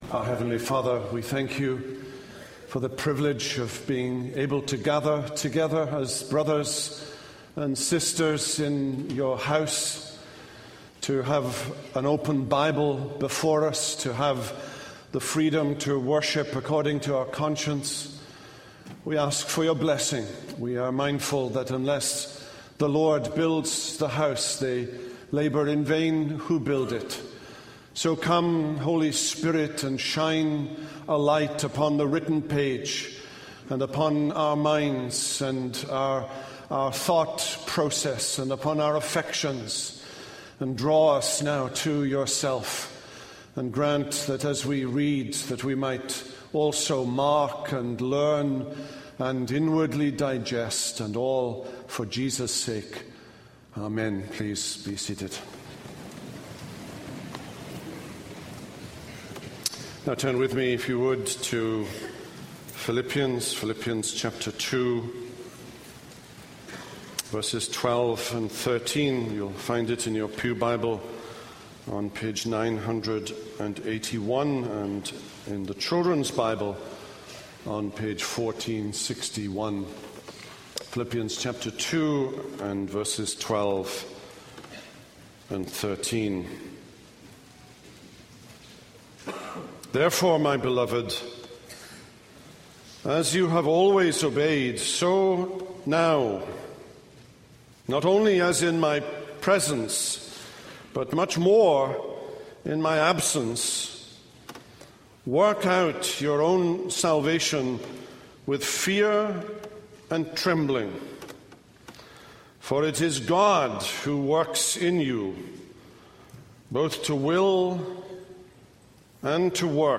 This is a sermon on Philippians 2:12-13.